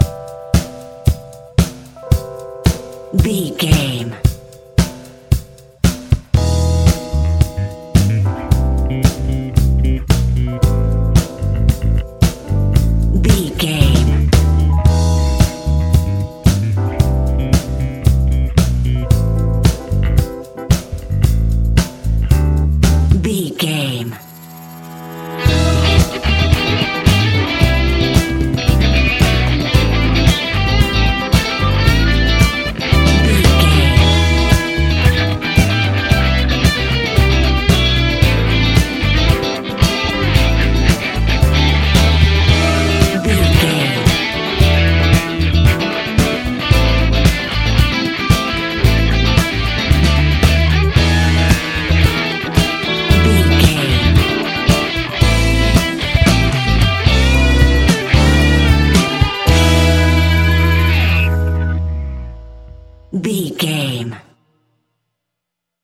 Ionian/Major
A♭
house
electro dance
synths
techno
trance
instrumentals